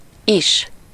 Ääntäminen
IPA: /ˈiʃ/